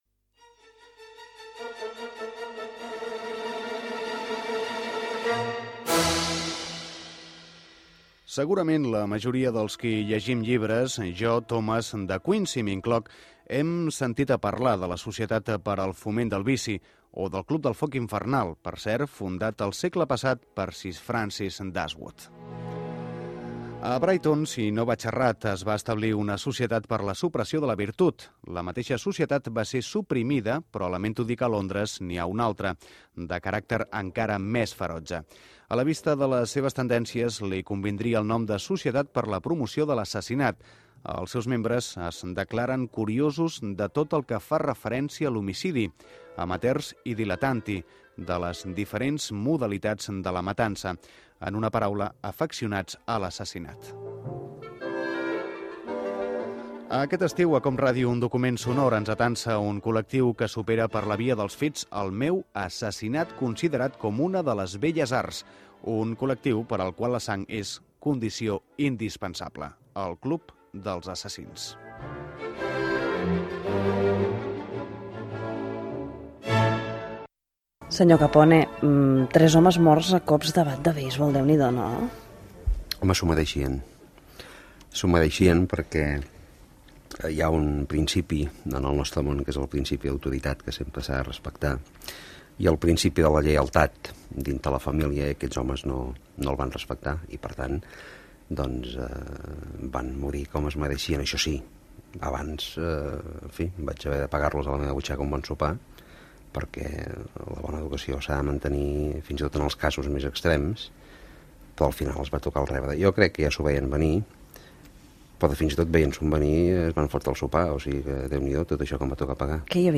Introducció del programa i entrevista ficcionada a Al Capone sobre els seus assassinats. El personatge el representa el polític Josep-Lluís Carod Rovira. Careta de sortida amb els noms de l'equip
Ficció
FM